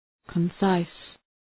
concise.mp3